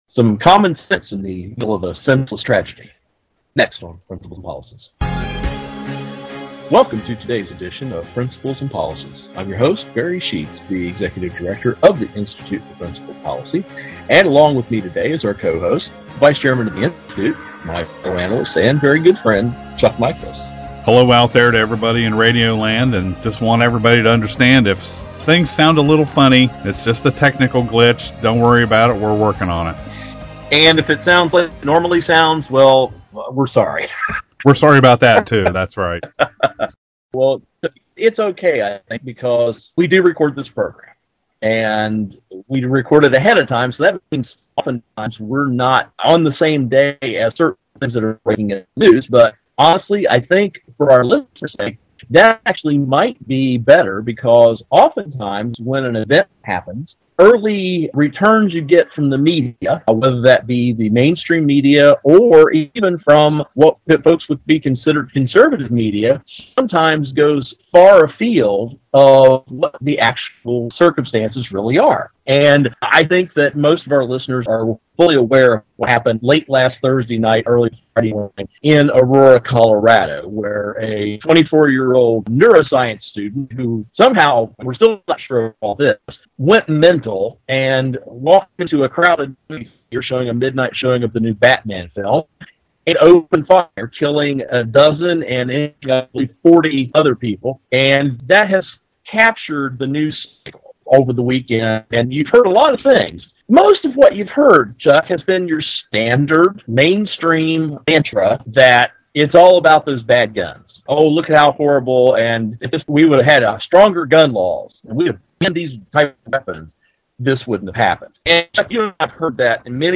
Our Principles and Policies radio show for Monday June 4, 2012.